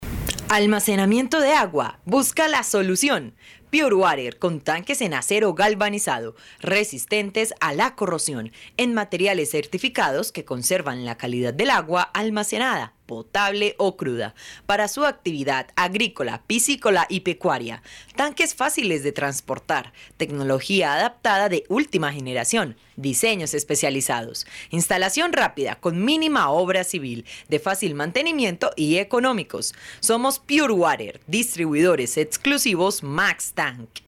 A soft voice with the kindness of a friend and the strength of a professional, from Colombia.
Sprechprobe: Sonstiges (Muttersprache):
I love speak with a blend between a soft and strong voice. Fresh, calm, sweet and deep when is needed.
Locución comercial_Purewater.mp3